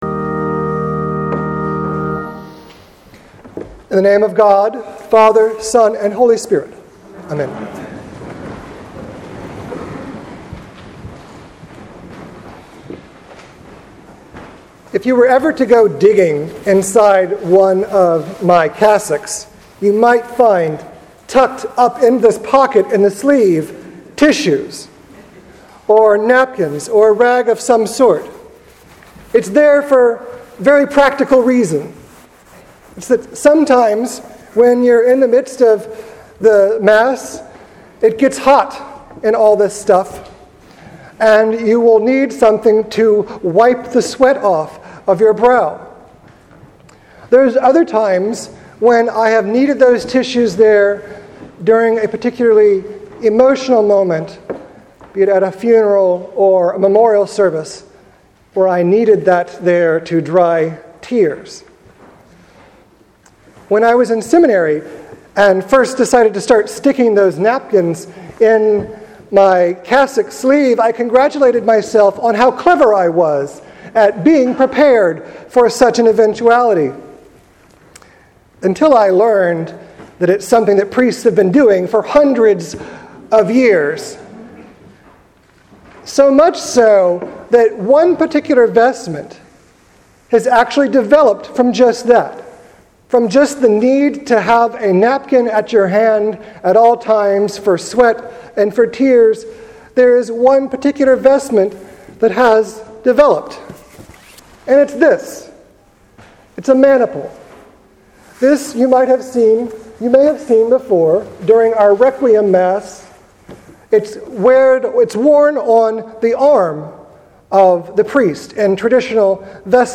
Yes, there will be maniples. Sermon for October 12th, 2014